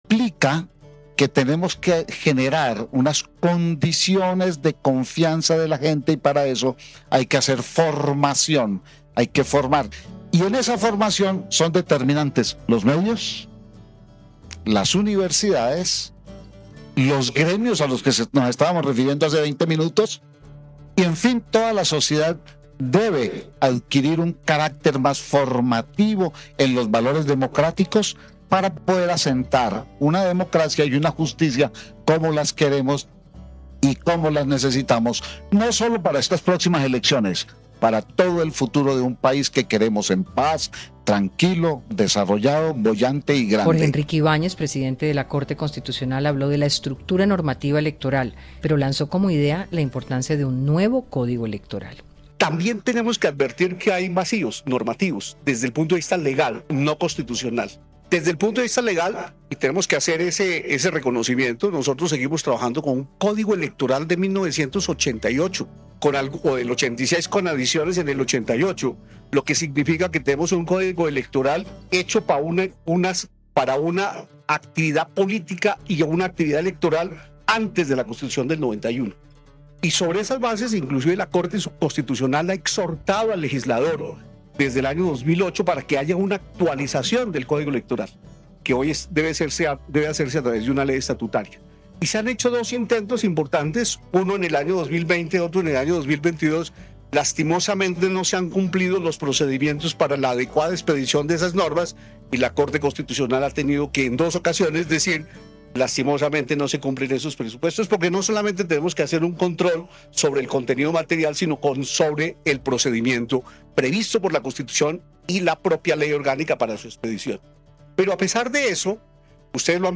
Hora20 recoge las mejores intervenciones de los participantes de la cuarta edición del festival que se celebró en Villa de Leyva entre el 18 y el 20 de septiembre.